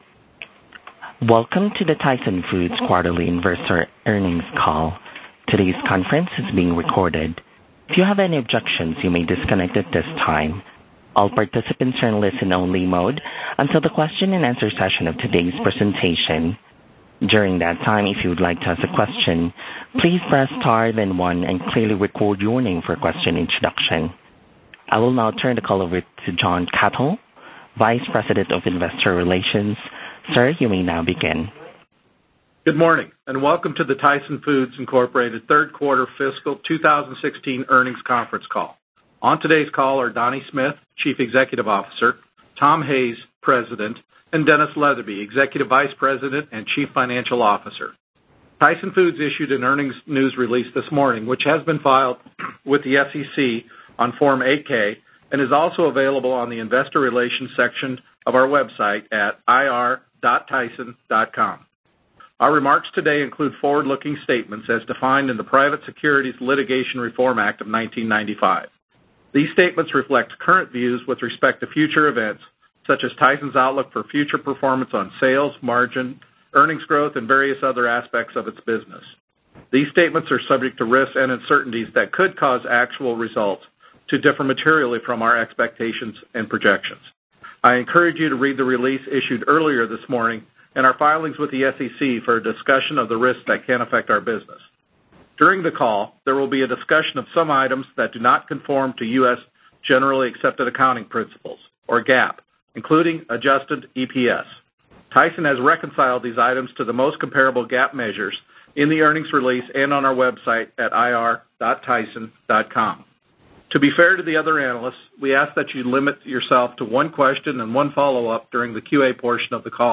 Tyson Foods Inc. - Q3 2016 Tyson Foods Earnings Conference Call